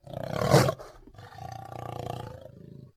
Add leopard sounds
sounds_leopard_roar_01.ogg